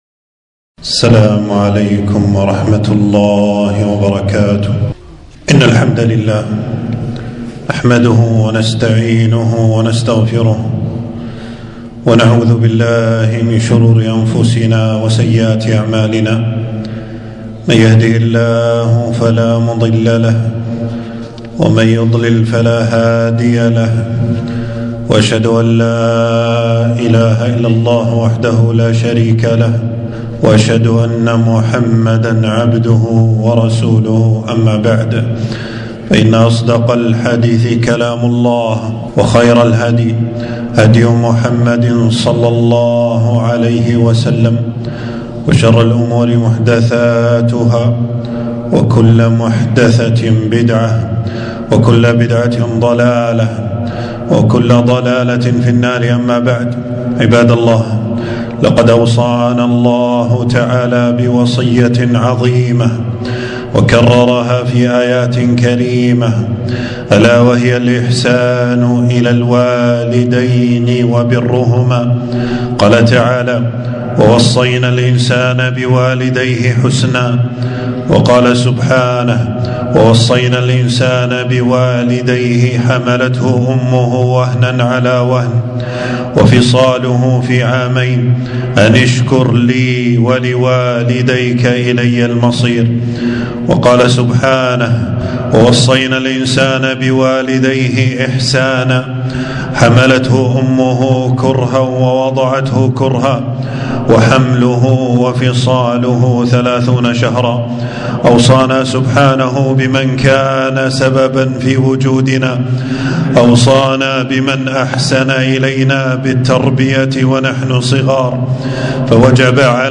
خطبة - الإحسان إلى الوالدين طريق الجنة